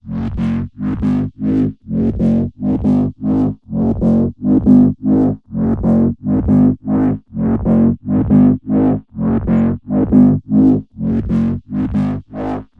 摇摆不定的贝司声 " 贝司重采样6
描述：音乐制作的疯狂低音
Tag: 重采样 重低音 音效设计 摇晃